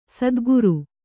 ♪ sadguru